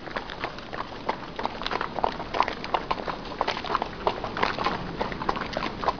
دانلود صدای حیوانات جنگلی 4 از ساعد نیوز با لینک مستقیم و کیفیت بالا
جلوه های صوتی